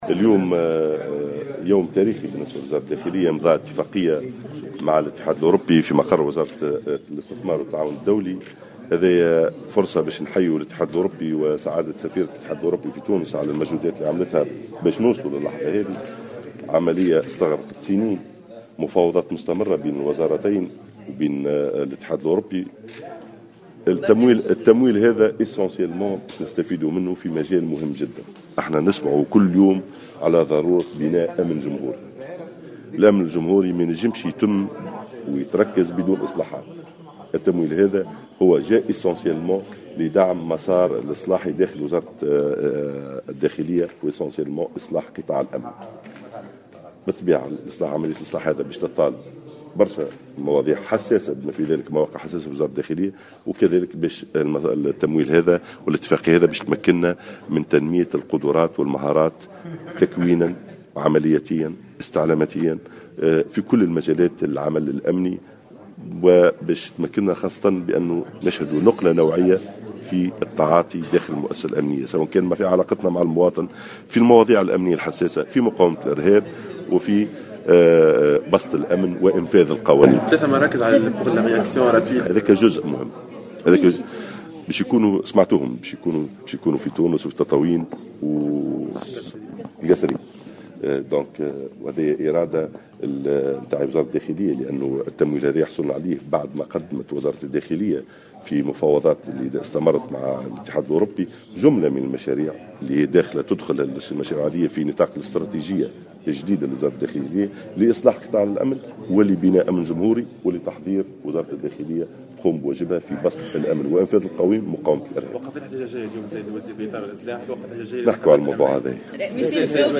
وبين وزير الداخلية في تصريح لمراسل الجوهرة أف أم أن هذا التمويل سيتم الإستفادة منه في مجال "بناء أمن جمهوري" من خلال تنفيذ جملة من الإصلاحات في قطاع الأمن، والتي ستطال مواقع حساسة في وزارة الداخلية، كما أعلن عن إحداث 3 مراكز أمنية جديدة للتدخل السريع بكل من تونس وتطاوين والقصرين.